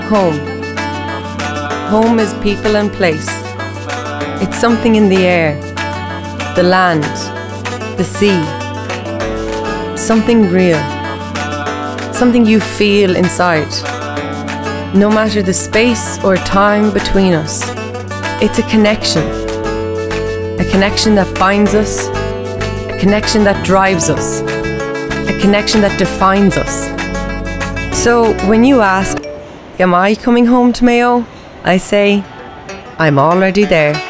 Female
20s/30s, 30s/40s
Irish Galway, Irish Neutral, Irish West